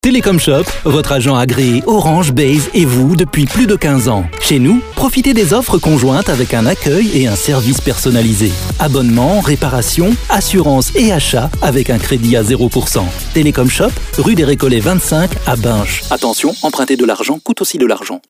spot publicitaire
complice , dynamique , sympa